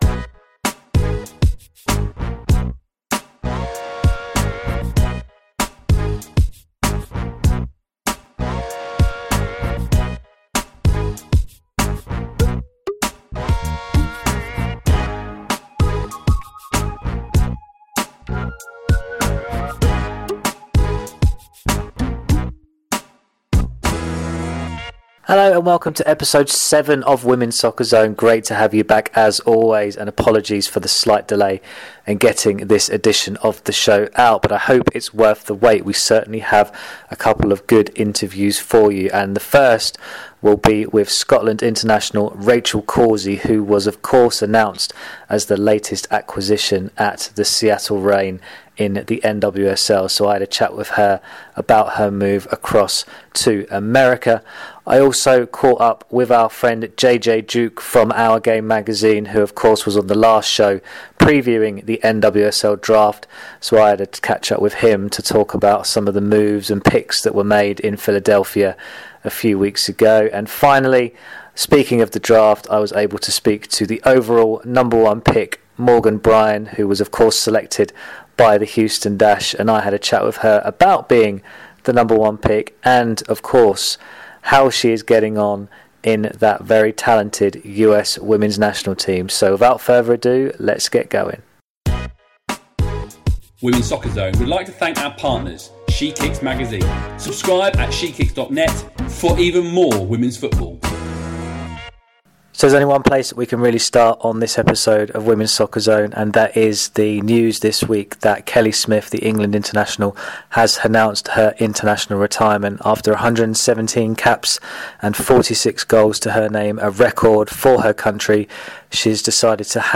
A packed show featuring interviews with new Seattle Reign defender, Rachel Corsie, and the overall number one pick in the NWSL draft, Morgan Brian.